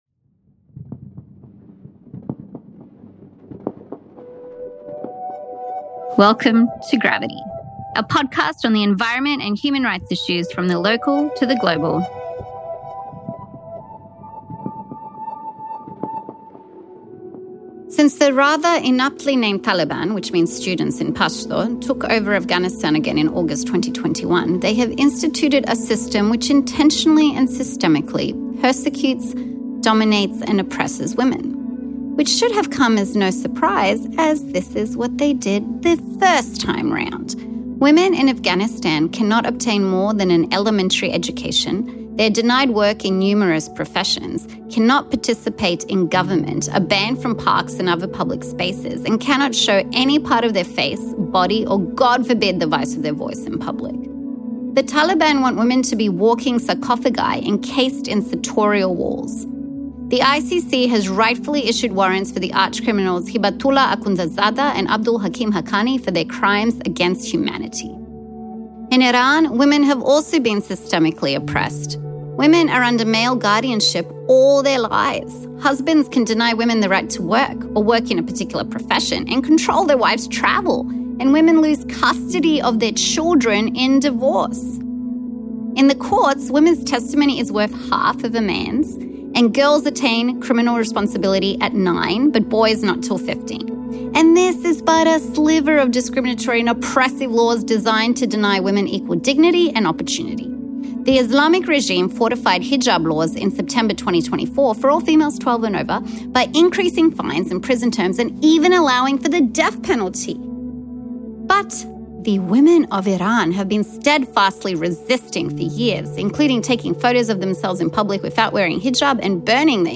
Discussing human rights and environmental issues from the legal, political and ethical fronts with interviews from activists, NGOs, authors and professors.